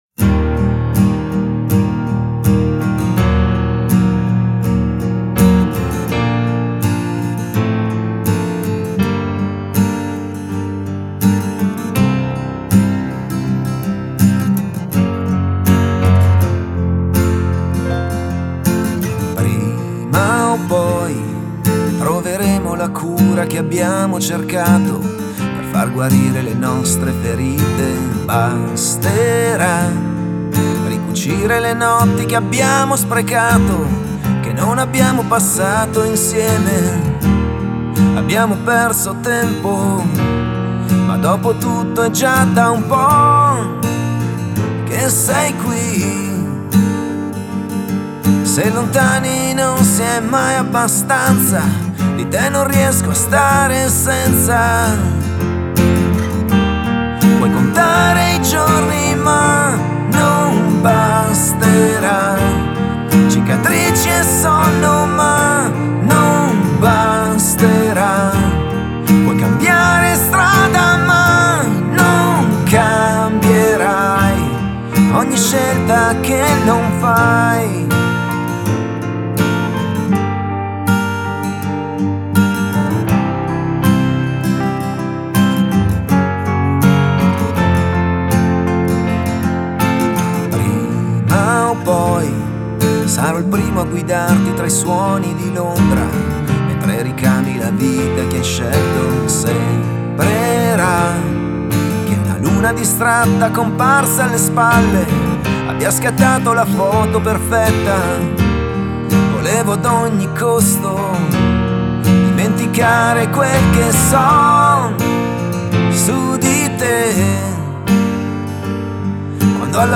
Genere: Cantautori.